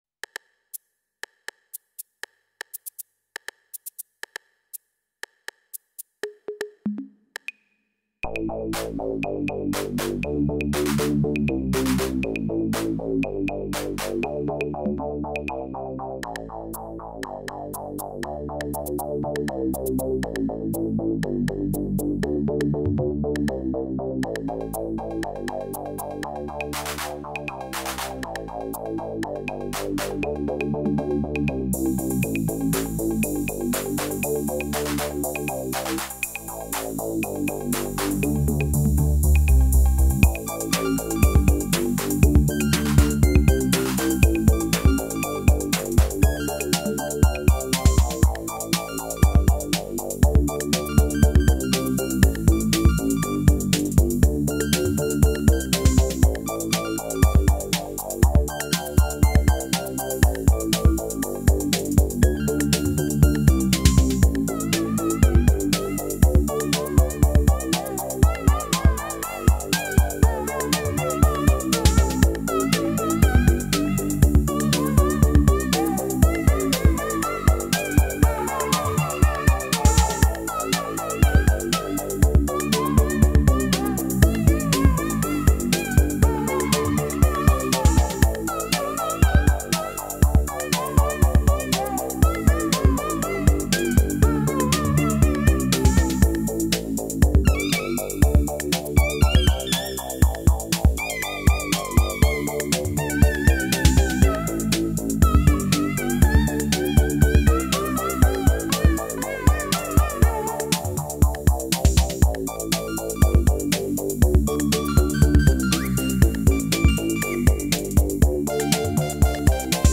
Just messing around really!